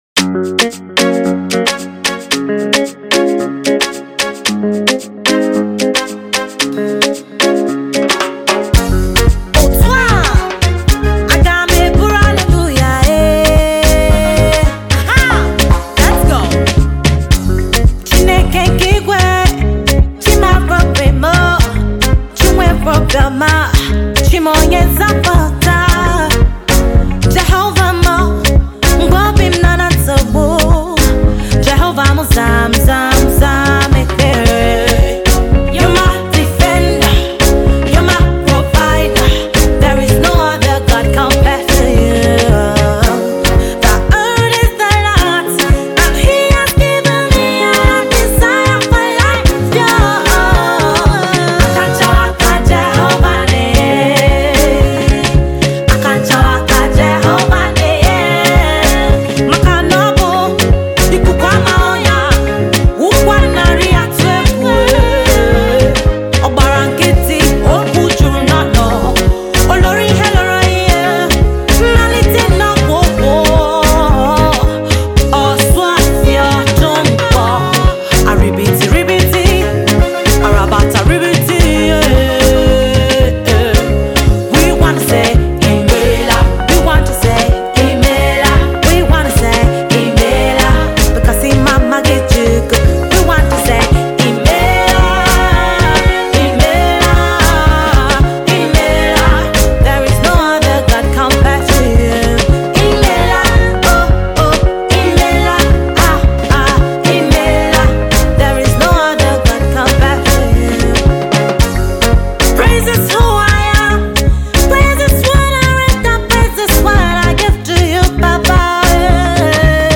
Contemporary Christian